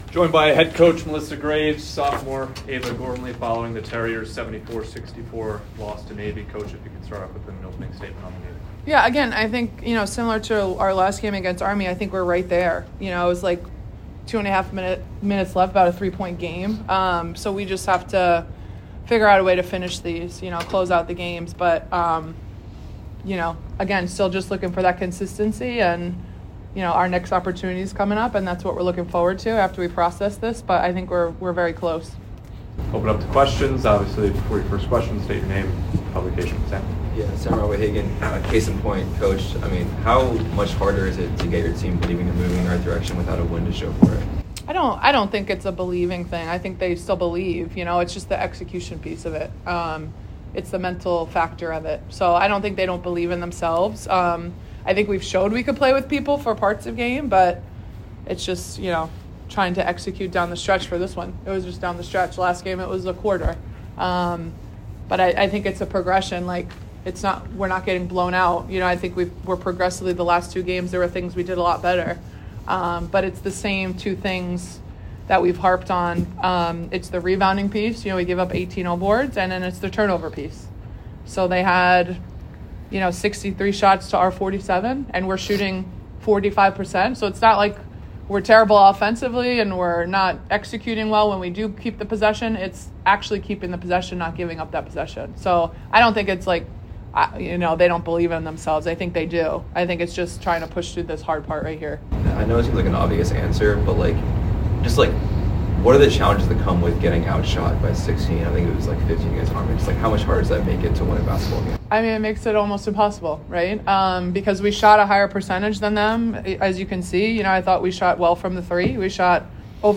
Women's Basketball / Navy Postgame Press Conference